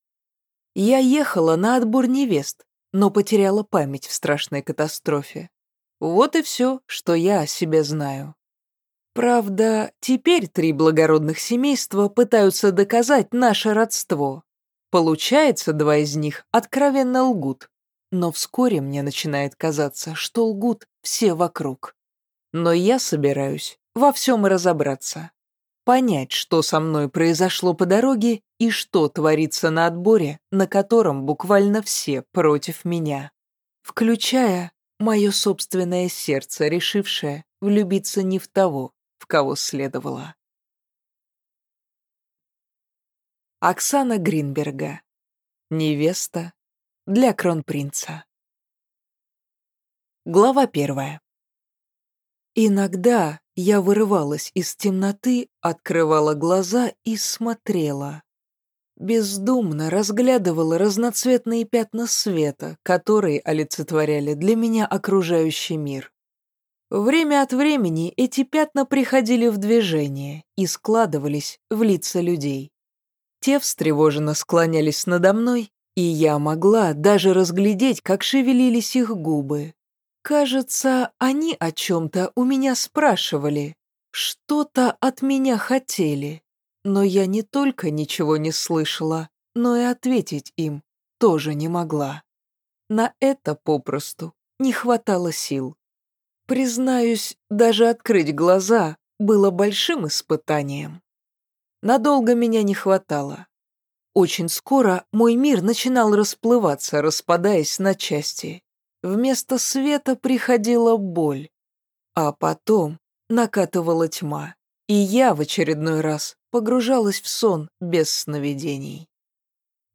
Аудиокнига Невеста для кронпринца | Библиотека аудиокниг
Прослушать и бесплатно скачать фрагмент аудиокниги